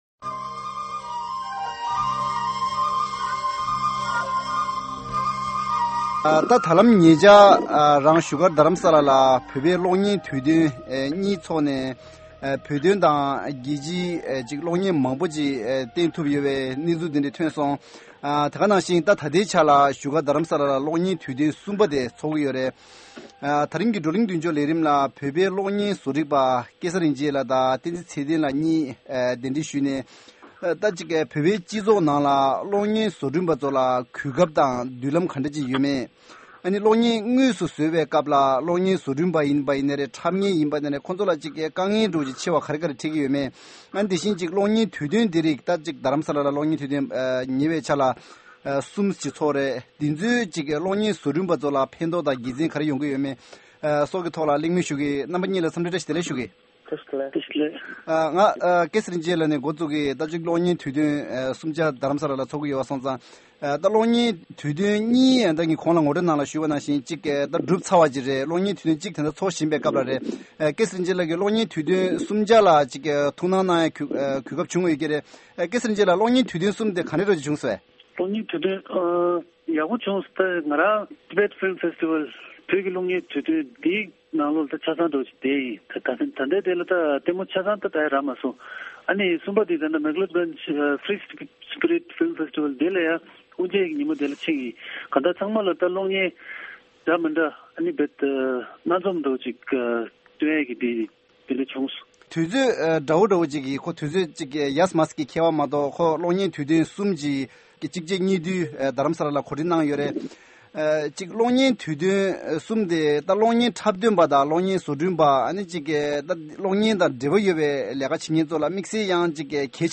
བགྲོ་གླིང་མདུན་ལྕོག་ལས་རིམ